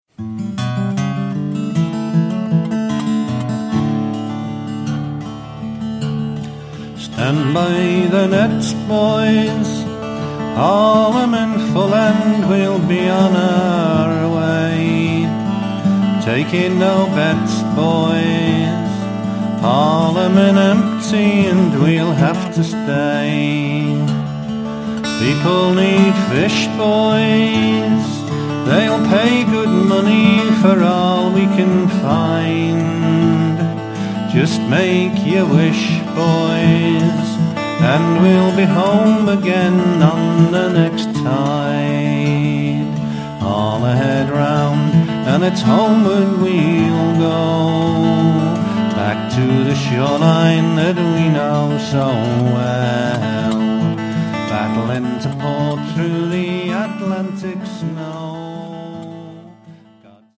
Everything I know about fishing as discovered at the local chip shop! I wrote this in the 1970s and I think the rise and fall of the melody mirrors the rise and fall of the sea.
atlantic fishermans song.mp3